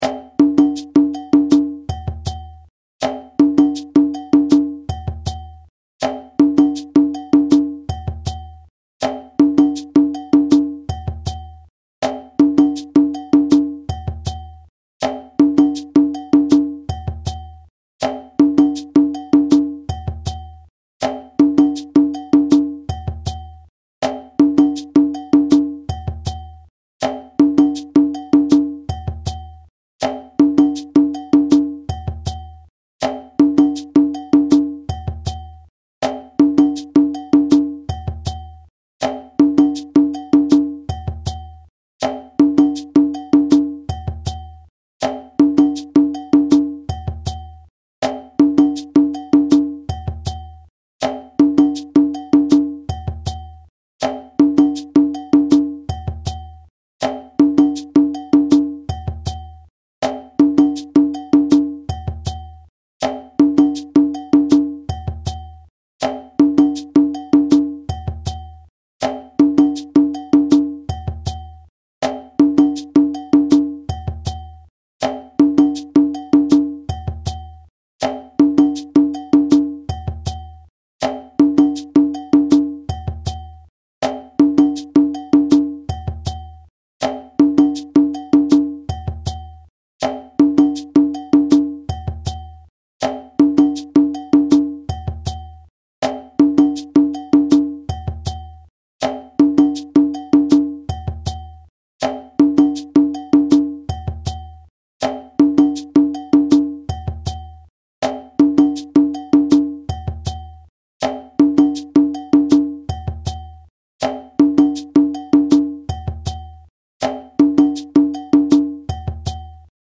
Break phrases are commonly used in djembe music to start or stop a rhythm or transition into another section.
4/4 Djembe Break #2
audio (shekeré & bell)
Djembe-Break-2-4-4-Slow-FREE.mp3